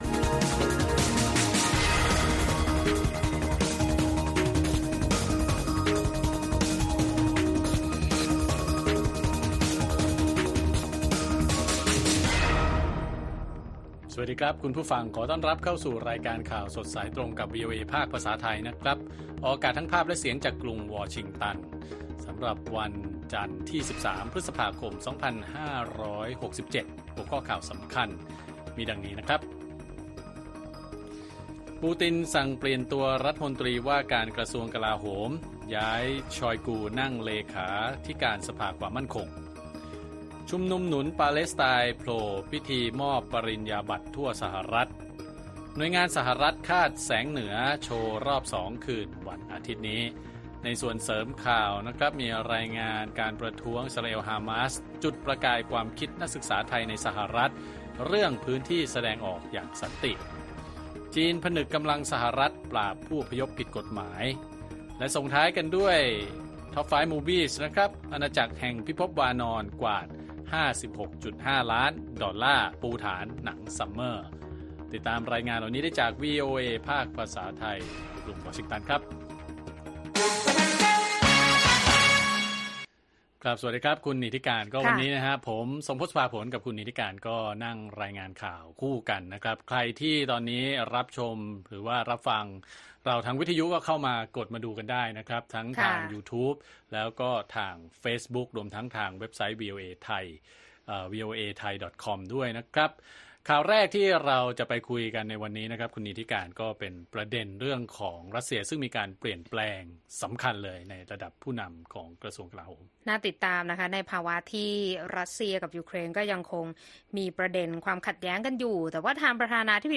ข่าวสดสายตรงจากวีโอเอไทย จันทร์ ที่ 13 พ.ค. 67